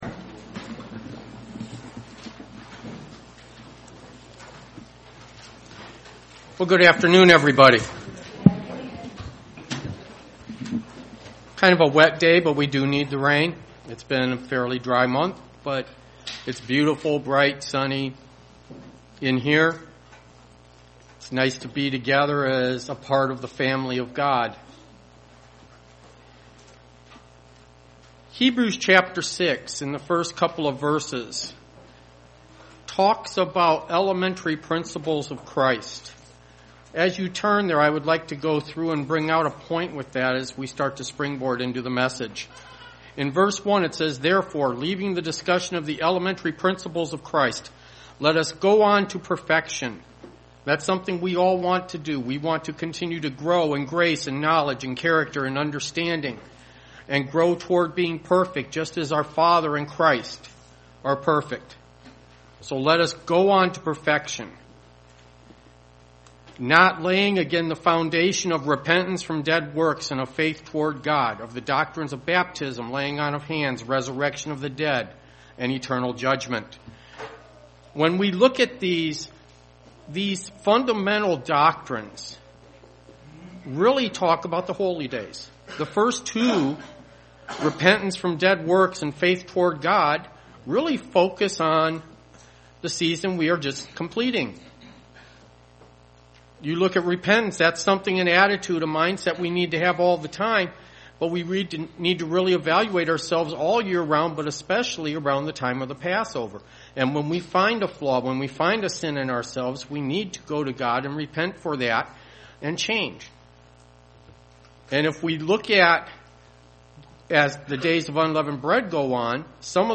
In this sermon listen to the account of Elijah. Discover how he had an immense amount of faith at times, and at other times little faith.
Given in Dayton, OH